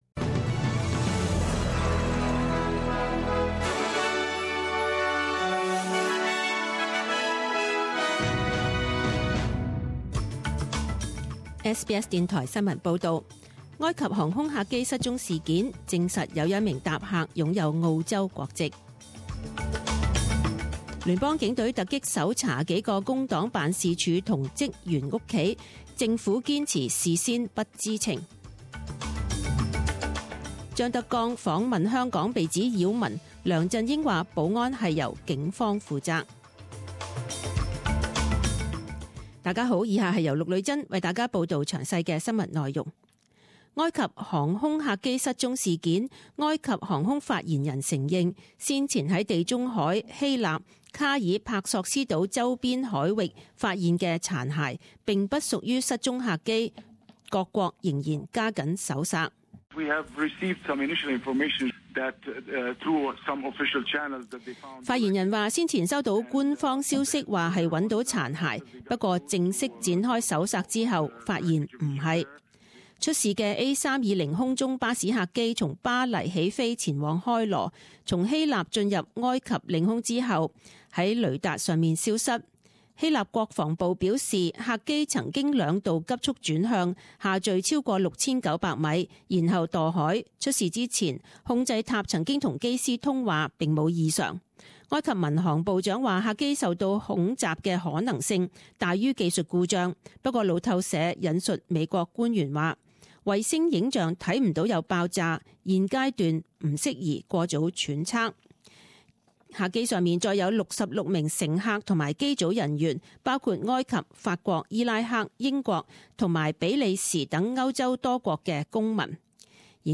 十點鐘新聞報導 （五月二十日）